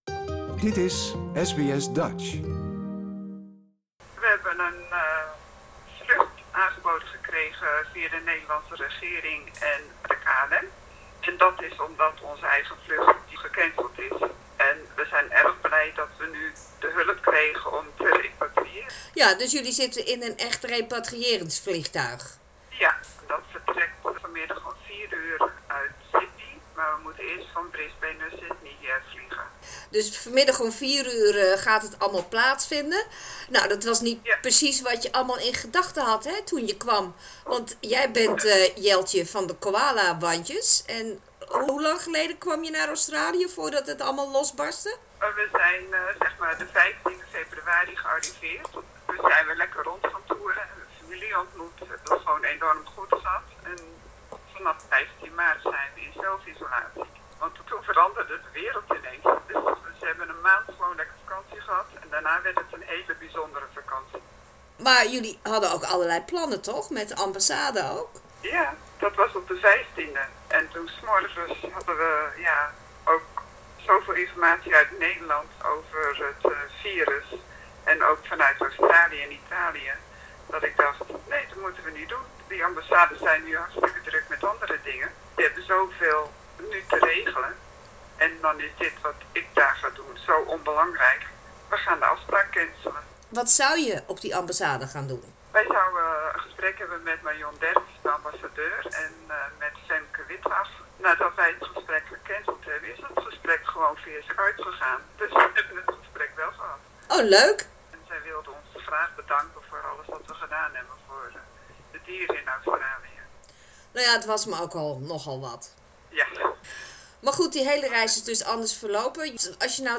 Dit interview is vanochtend opgenomen toen alles nog koek en ei was.